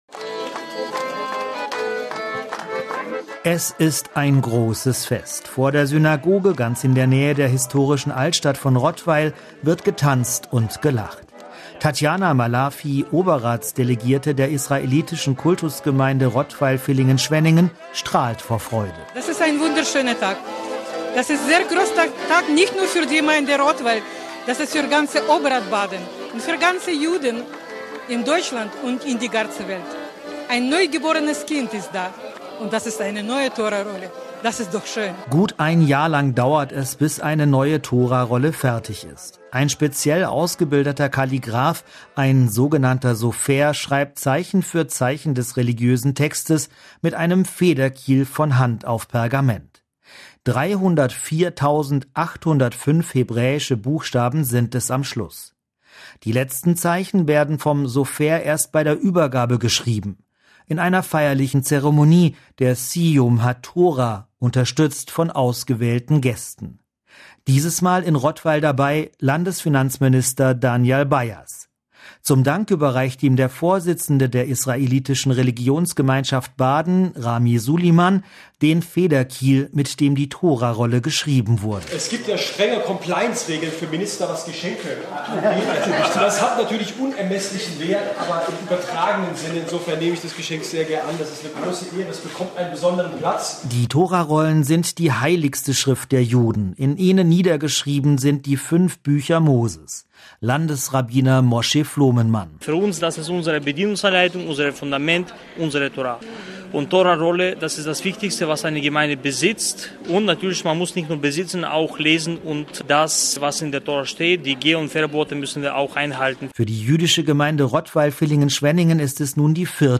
Im Rahmen einer feierlichen Zeremonie mit Musik und Tanz wurde die neue Torarolle durch die israelitische Kultusgemeinde Rottweil/Villingen-Schwenningen in der Rottweiler Synagoge in Empfang genommen.